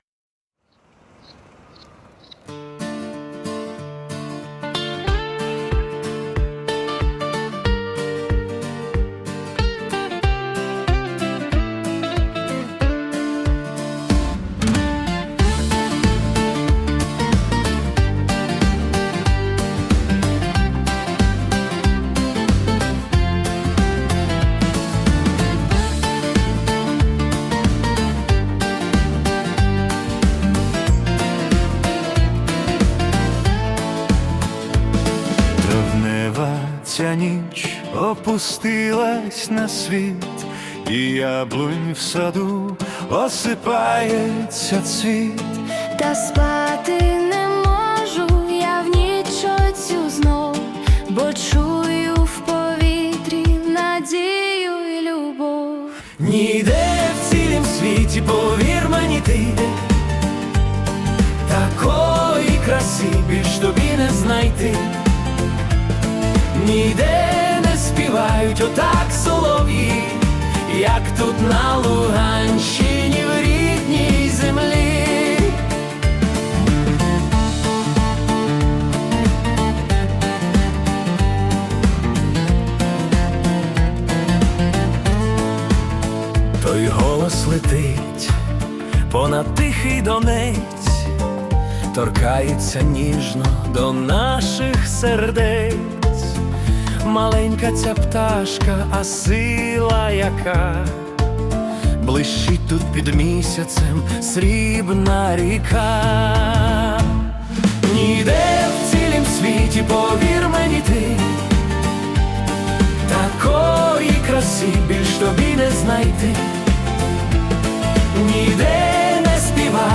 Waltz / Classical
Вальс над Дінцем
Музика наповнена світлом.